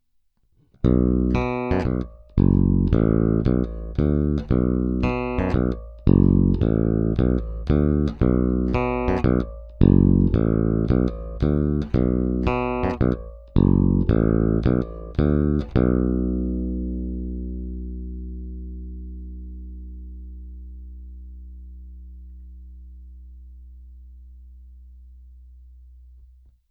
Zvuk je pevný, spíše moderního charakteru ala Spector.
Následující ukázky jsou provedeny rovnou do zvukovky, použity jsou struny Elixir Nanoweb ze sady 45-105 ve výborném stavu.
Kromě normalizace nebyly provedeny žádné dodatečné úpravy.
Stejné ukázky s korekcemi nastaveným naplno, jak basy, tak výšky.
Oba snímače